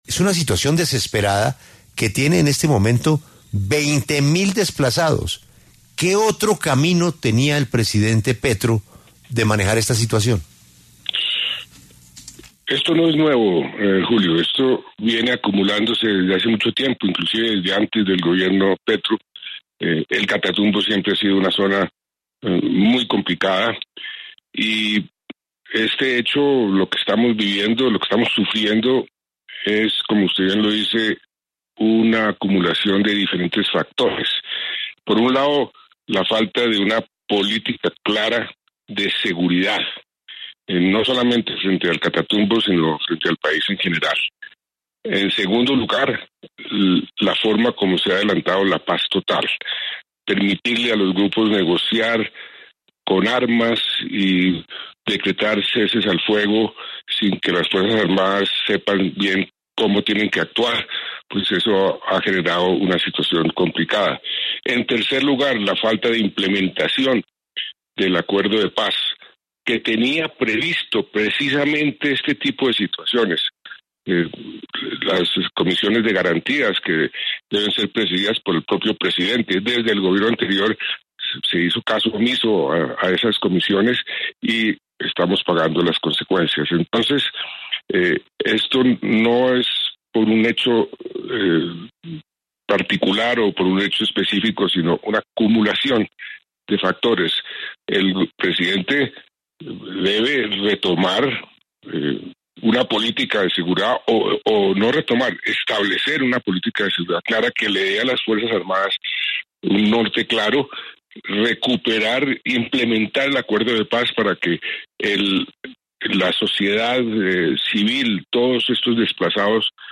Juan Manuel Santos, expresidente de Colombia, conversó con La W, con Julio Sánchez Cristo, a propósito de la crisis de seguridad en el Catatumbo y en Colombia ante la violencia desatada por los grupos armados.